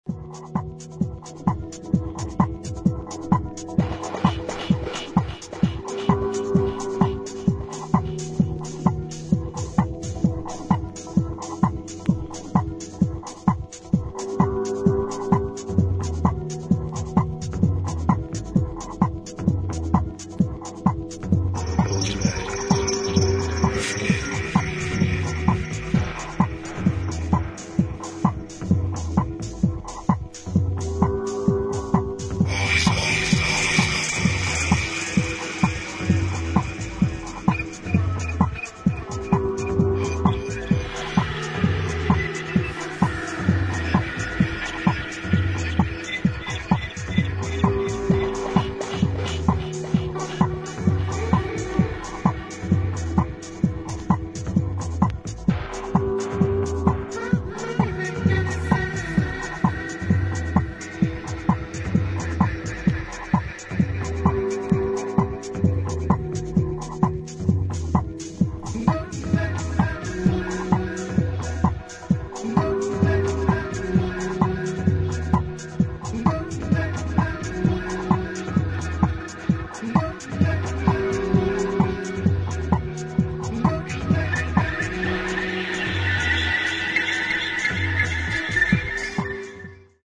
先見性のあるオリジナリティと、普遍的なダンスミュージックの魅力を内包させた秀逸な一枚です。